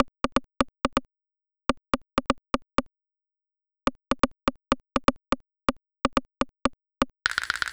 Bleep Hop Click Loop.wav